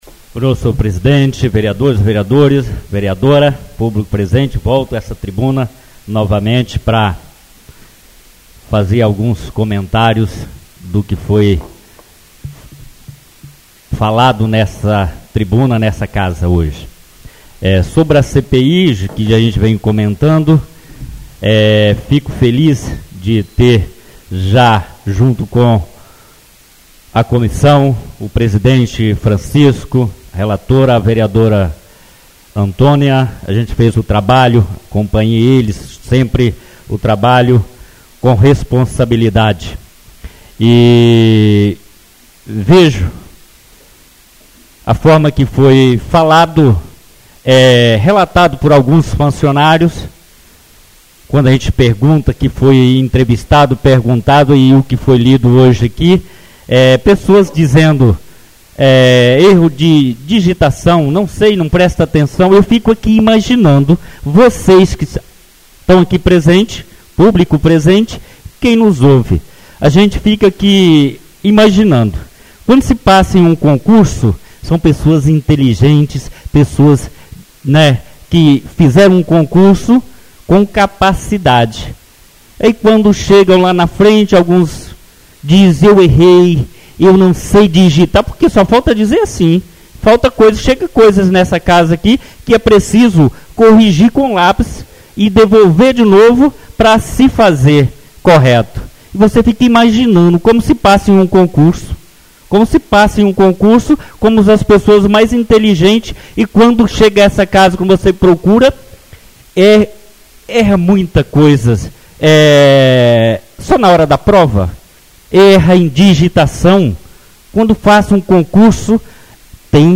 Oradores das Explicações Pessoais (28ª Ordinária da 3ª Sessão Legislativa da 6ª Legislatura)